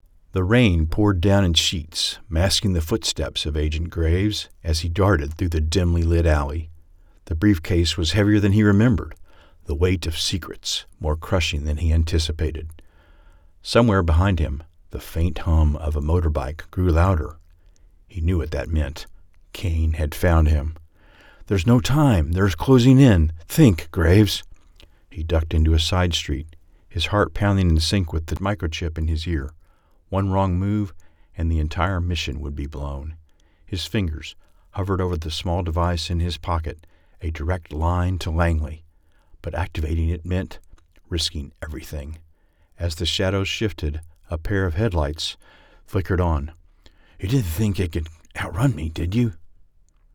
Spy Espionage audiobook
Spy-espionage-audiobook-1.mp3